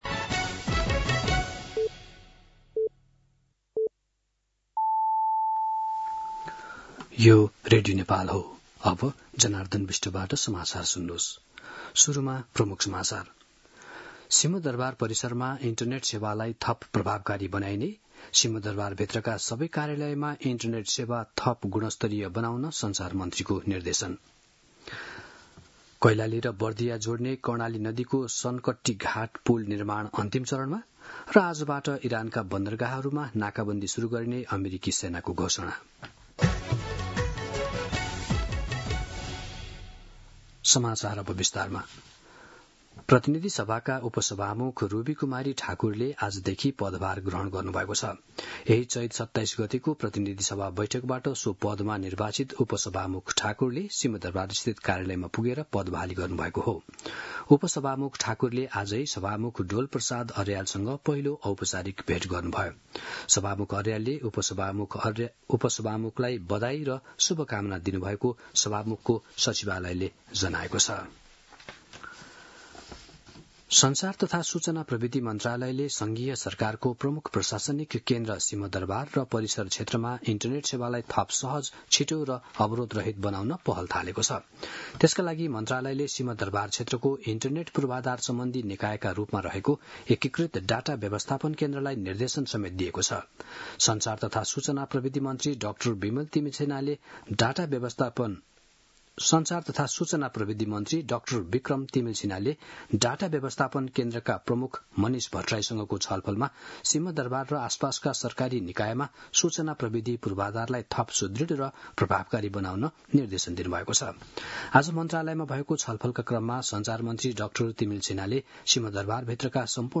दिउँसो ३ बजेको नेपाली समाचार : ३० चैत , २०८२